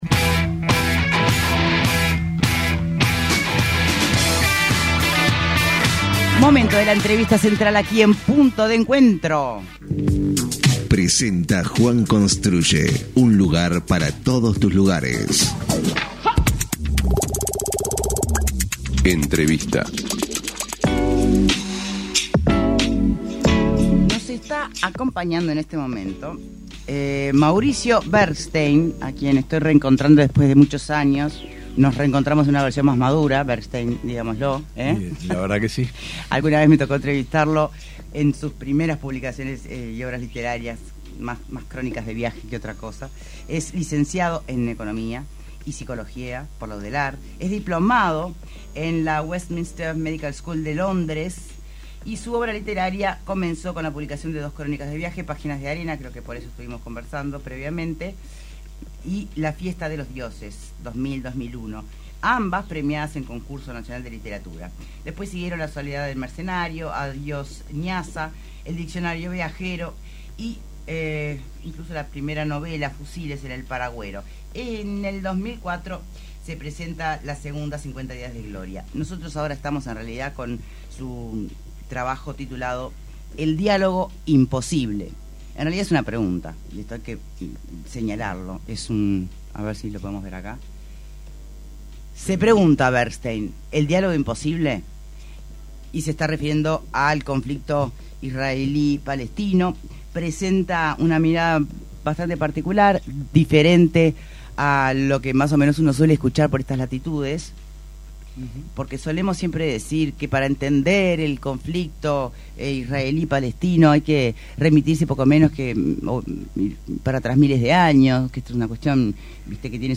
En entrevista con Punto de Encuentro